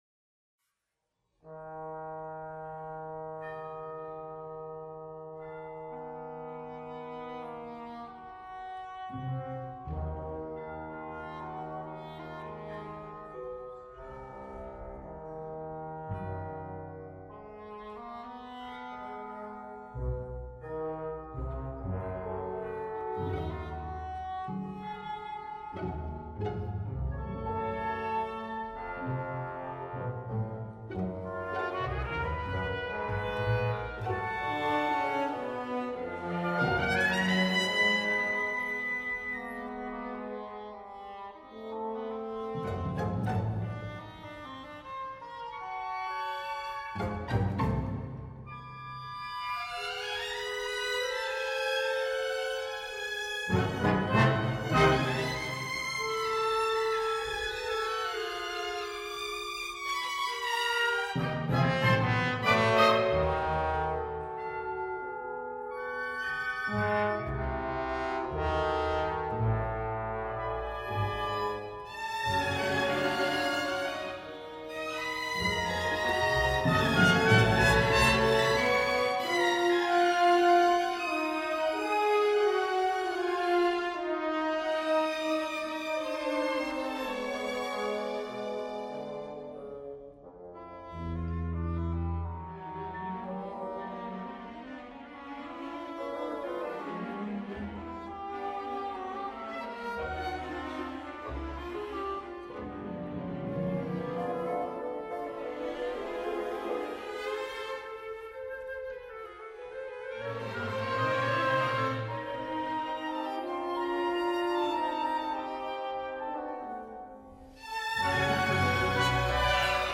orchestra 2011-14